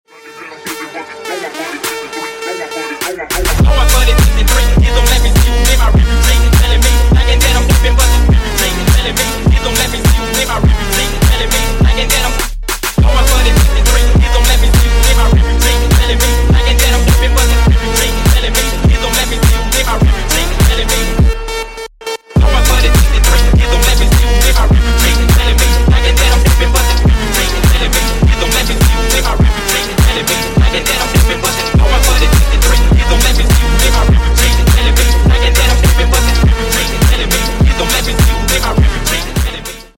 Электроника » Фонк